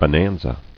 [bo·nan·za]